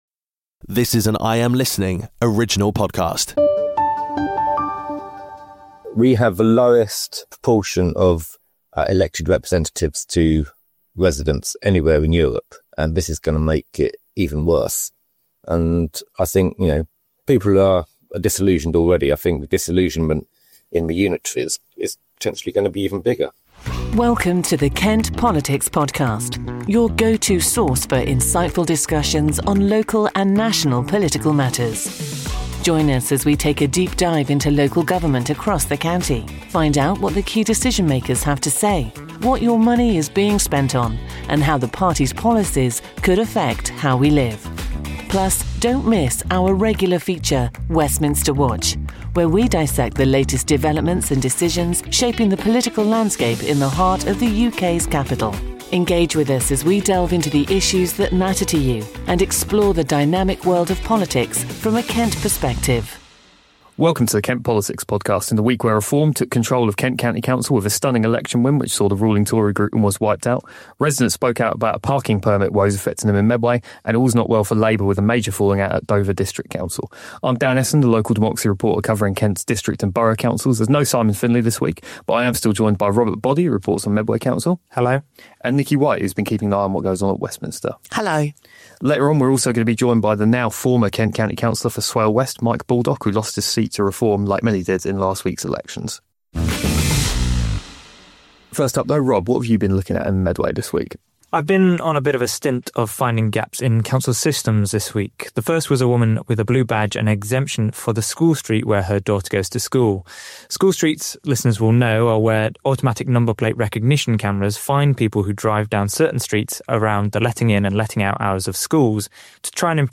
EP66: KCC under new leadership, Labour bother in Dover and special guest Cllr Mike Baldock - IM Listening